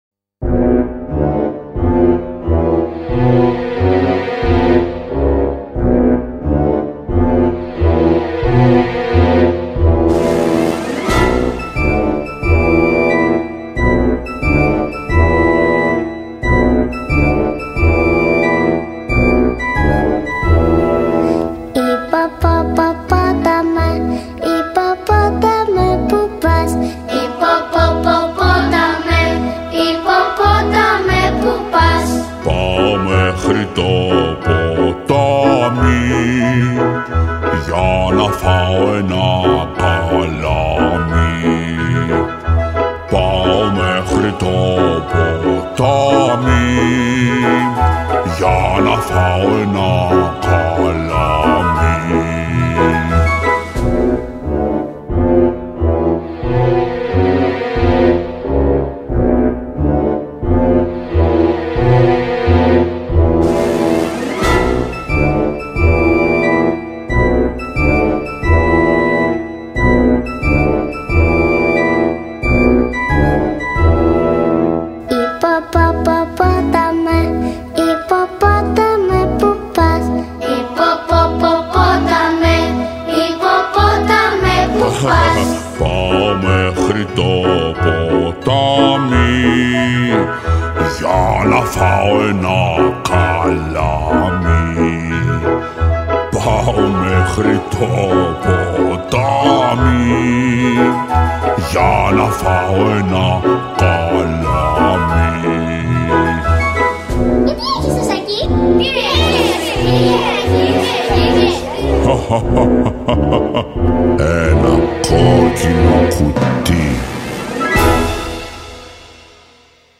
μελοποιημένα αποσπάσματα
αλλά και παιδιά δημοτικών σχολείων.